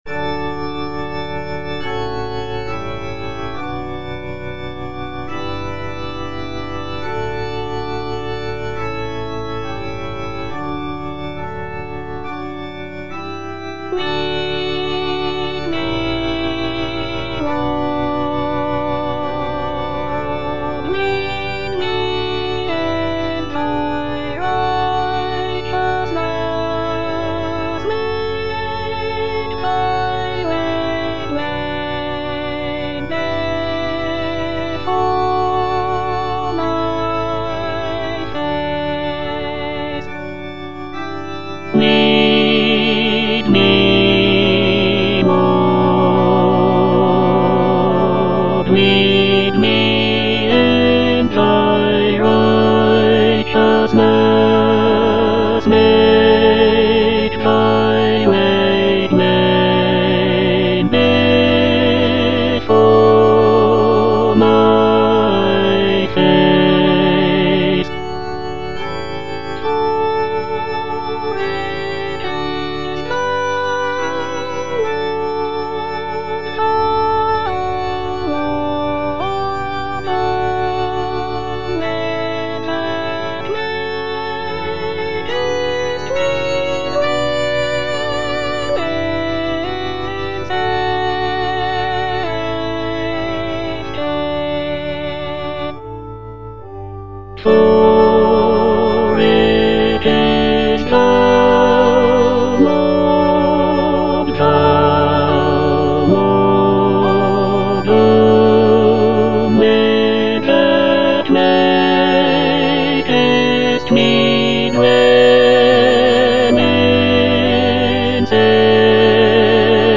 S.S. WESLEY - LEAD ME, LORD Tenor (Emphasised voice and other voices) Ads stop: auto-stop
"Lead me, Lord" is a sacred choral anthem composed by Samuel Sebastian Wesley in the 19th century.
The music is characterized by lush choral textures and expressive dynamics, making it a popular choice for church choirs and worship services.